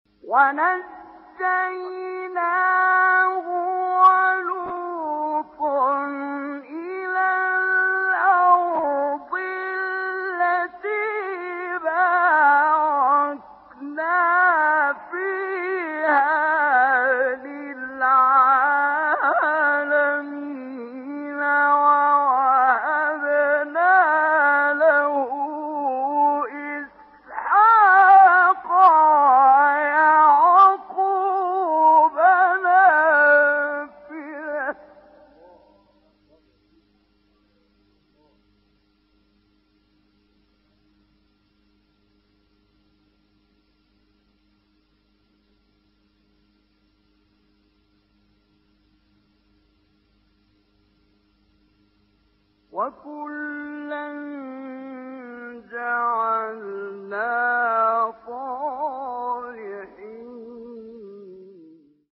سوره : انبیاء آیه : 71-72 استاد : حمدی زامل مقام : حجاز قبلی بعدی